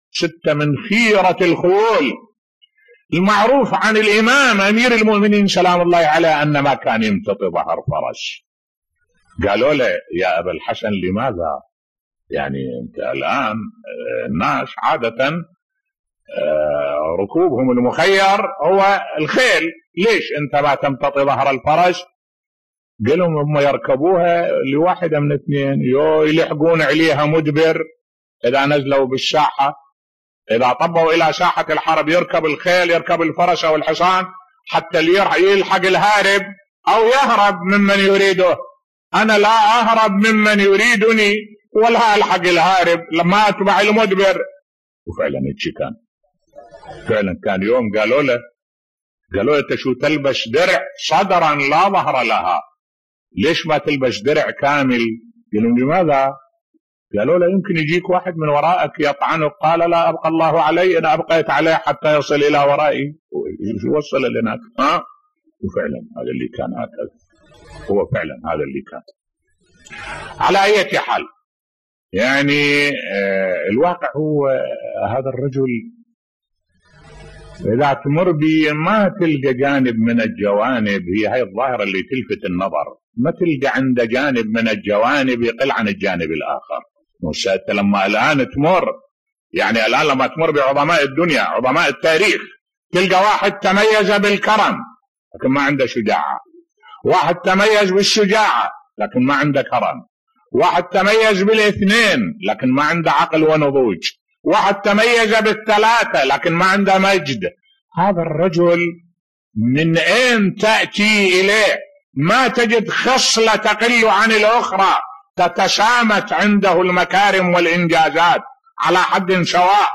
ملف صوتی لماذا كان الامام علي لا يركب الحصان بصوت الشيخ الدكتور أحمد الوائلي